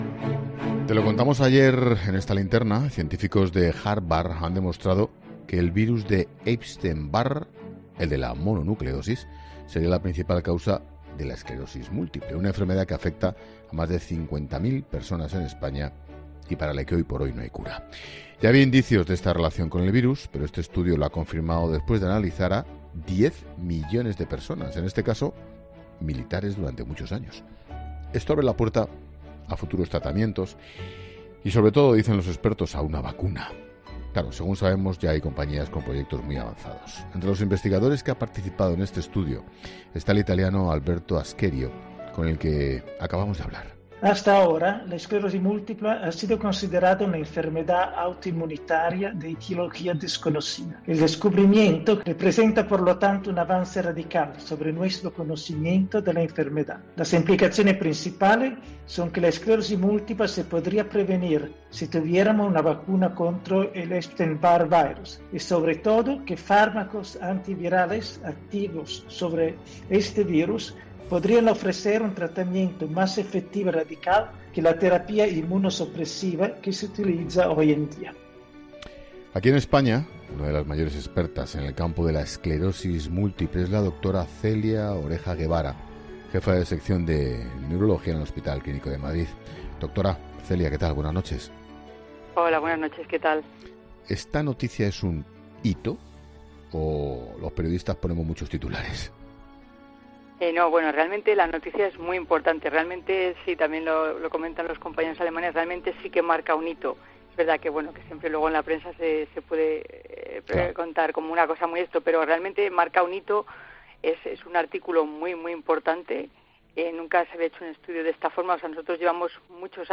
Uno de los investigadores del estudio sobre la esclerosis múltiple explica el hallazgo: "Es un avance radical"